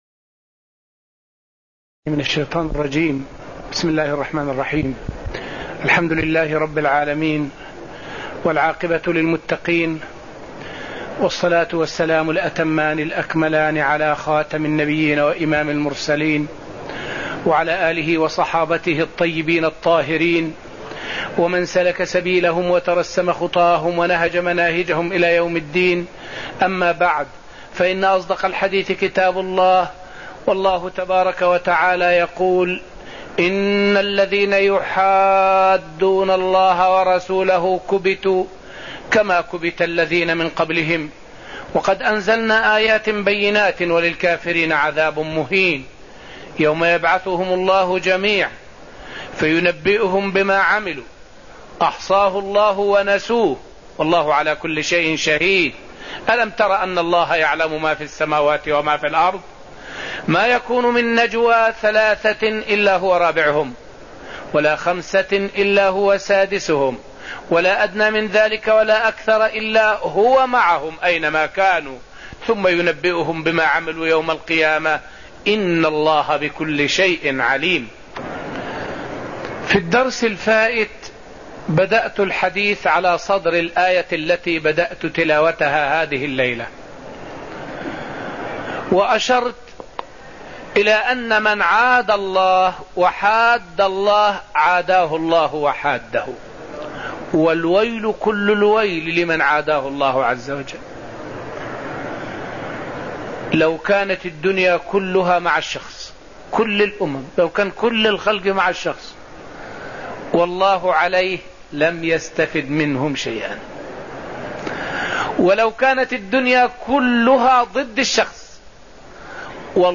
الدرس الأول من سلسلة دروس تفسير سورة المجادلة والتي ألقاها الشيخ رحمه الله في رحاب المسجد النبوي الشريف وقد فسر فيه الشيخ الآيات من أول السورة حتى قوله تعالى: {فمن لم يجد فصيام شهرين متتابعين} الآية 4.